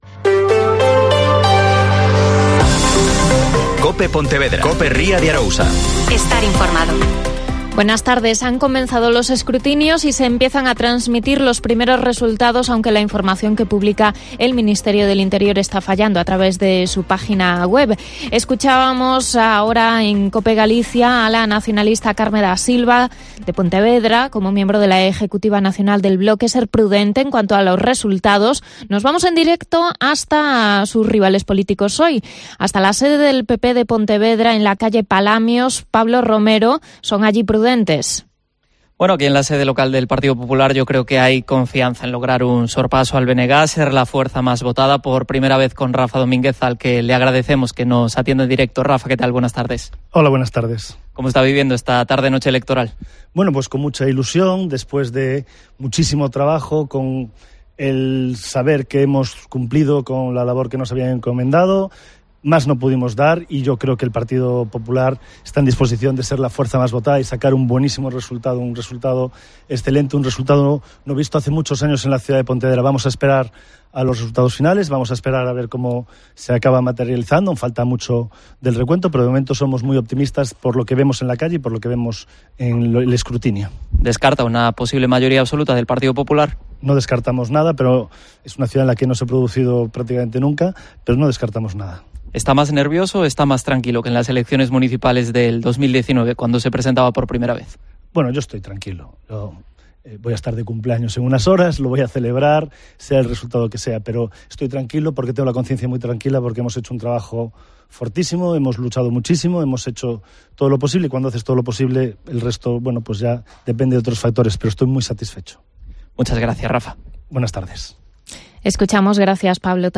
Especial Elecciones Municipales 2023 (Informativo 21,23h)